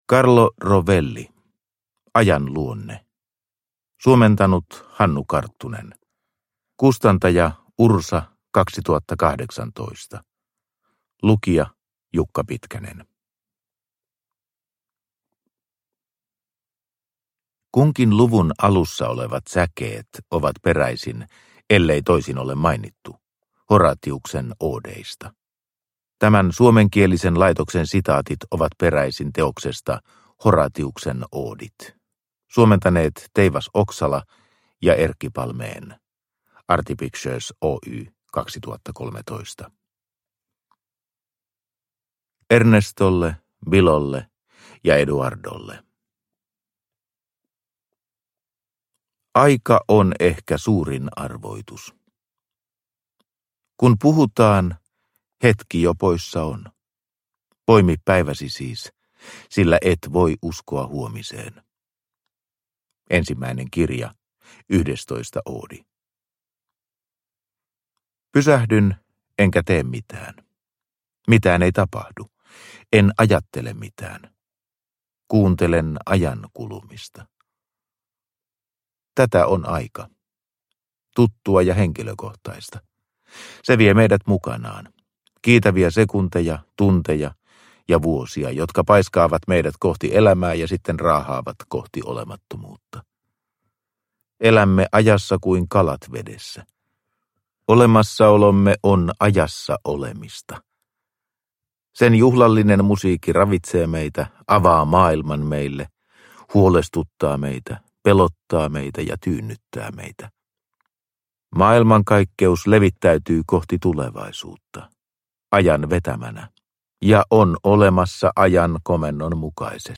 Ajan luonne – Ljudbok – Laddas ner